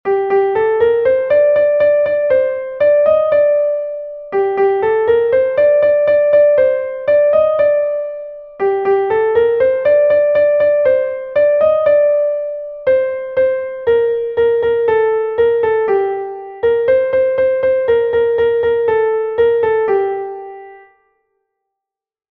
Dantzakoa
A-B